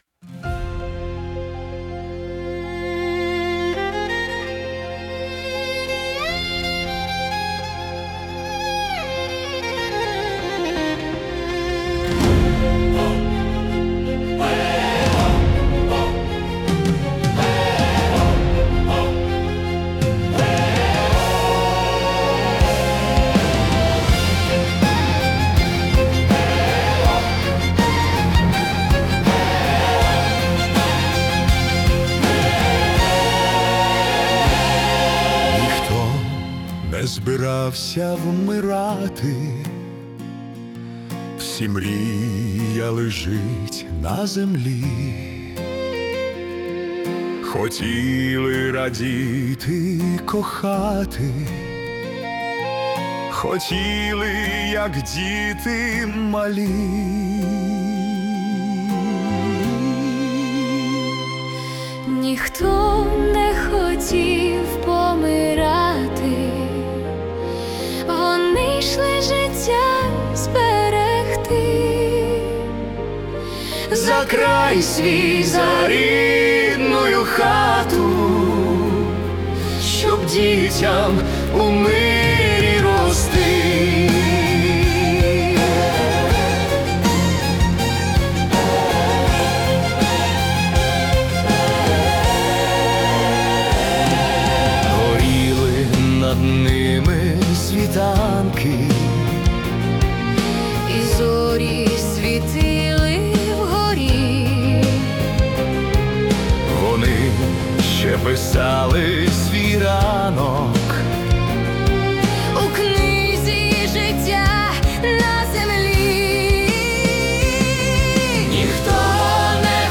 🎵 Жанр: Heroic Ballad / Requiem
Композиція (80 BPM) звучить як гімн пам'яті та скорботи.